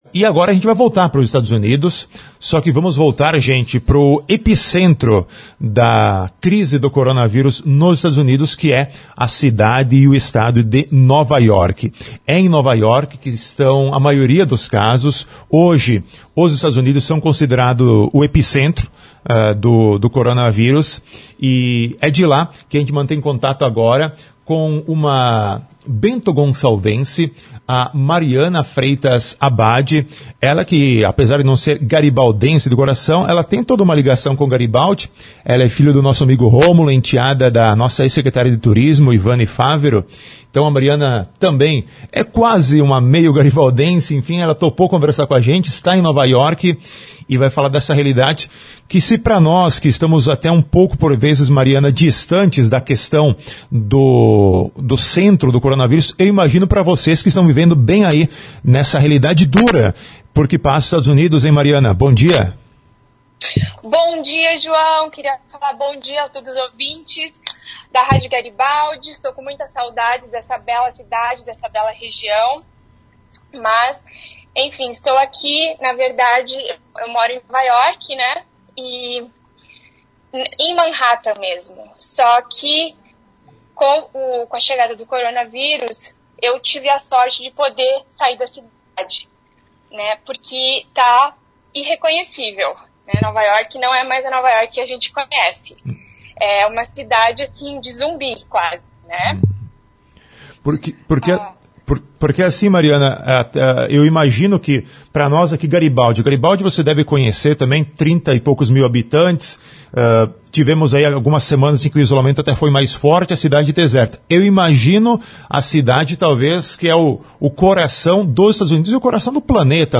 Ouça a entrevista na íntegra clicando em "Ouvir notícia".